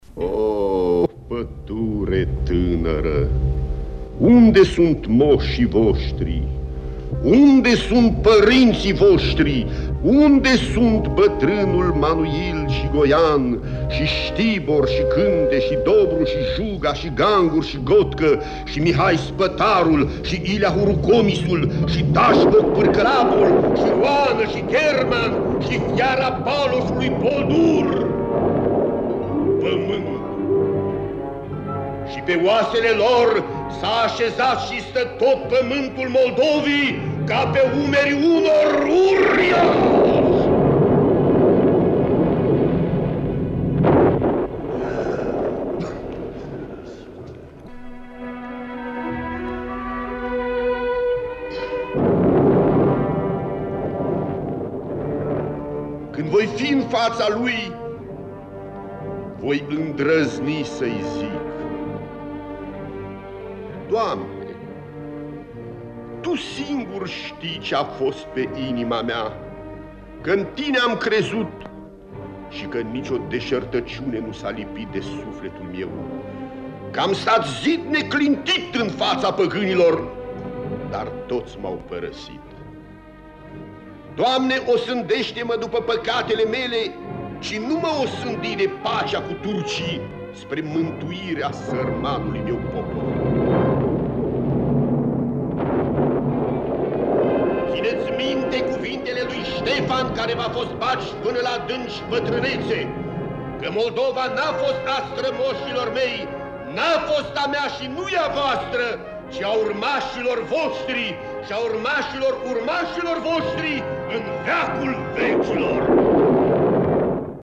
Simţim vibraţia unui sentiment – prin interpretarea lui George Calboreanu:
MP3 voce George Calboreanu … 1’57”
Apus-de-Soare-Voce-Calboreanu.mp3